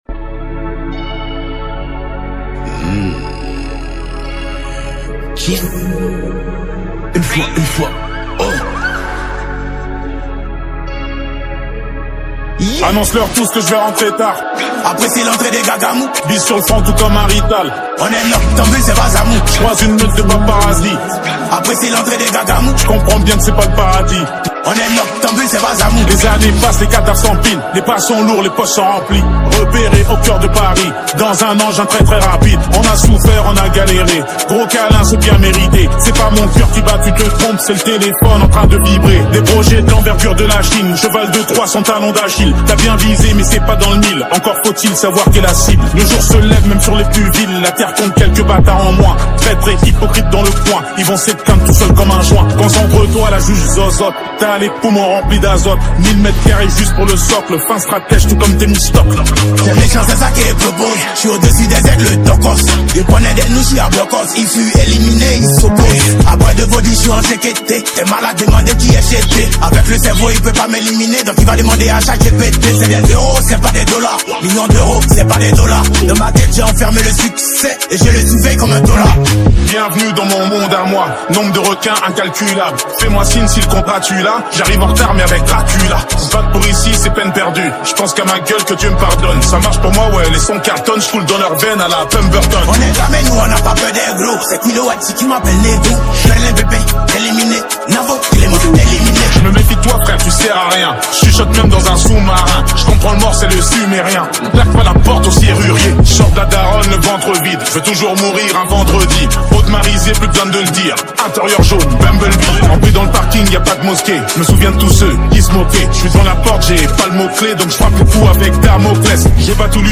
| Rap Hip-Hop